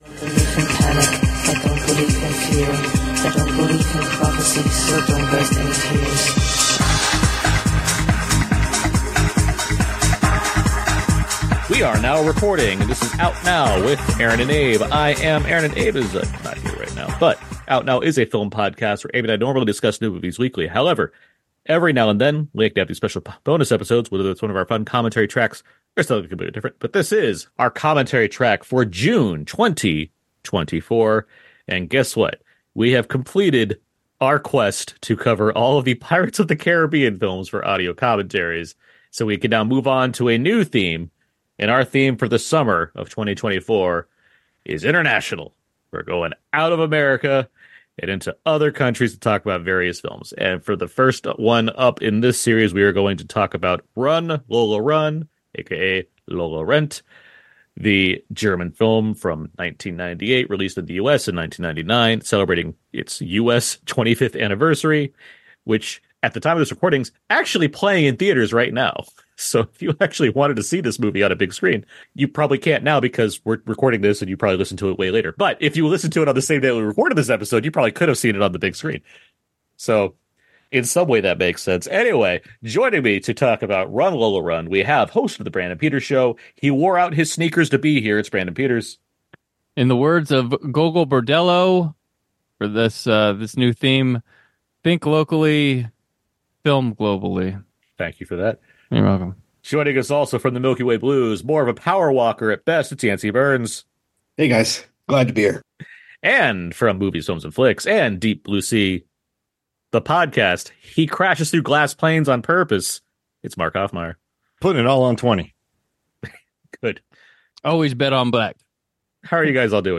There’s plenty of conversation revolving around Tykwer’s visual and thematic choices, Potente’s performance, other features hitting around the same time, and lots of fun tangents as well.